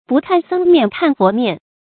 注音：ㄅㄨˋ ㄎㄢˋ ㄙㄥ ㄇㄧㄢˋ ㄎㄢˋ ㄈㄛˊ ㄇㄧㄢˋ